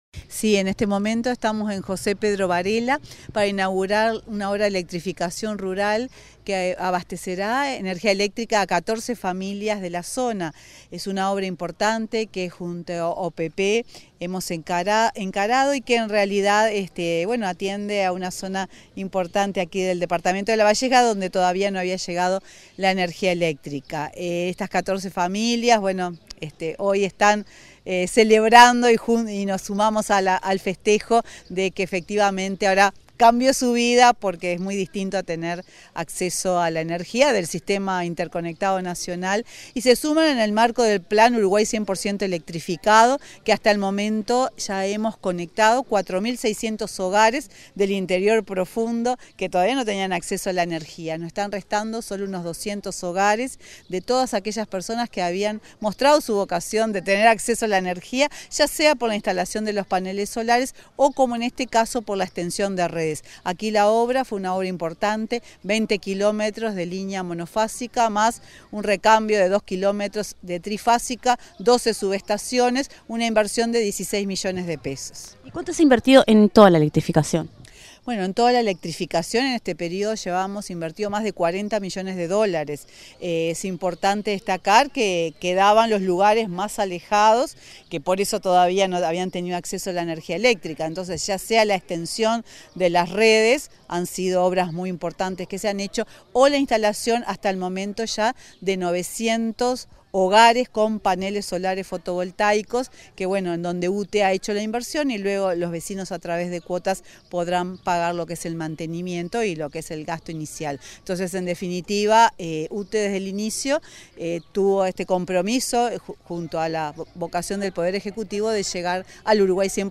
Entrevista de la presidenta de UTE, Silvia Emaldi